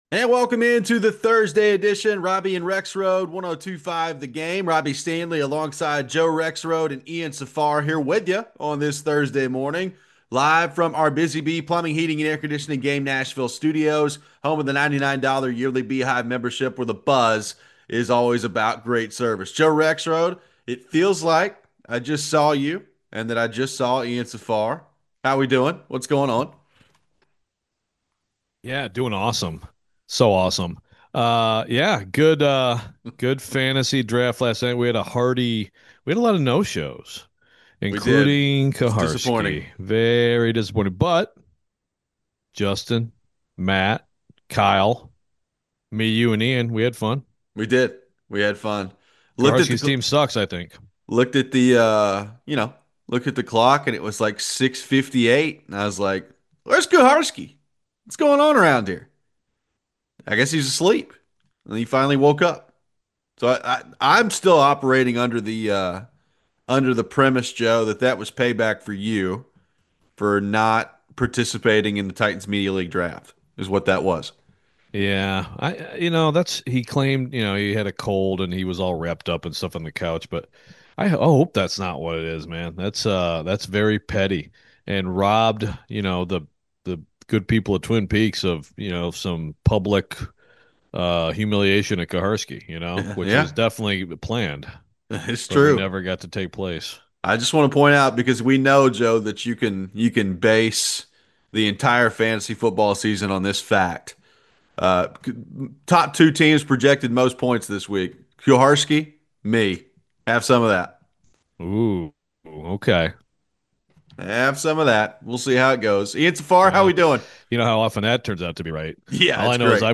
The NFL kicks off the season tonight with the Eagles vs Cowboys. Some news yesterday that the Redzone channel is going to start showing commercials. We have our official Titans predictions for the season and take your phones.